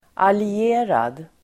Uttal: [ali'e:rad]